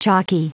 Prononciation du mot chalky en anglais (fichier audio)
Prononciation du mot : chalky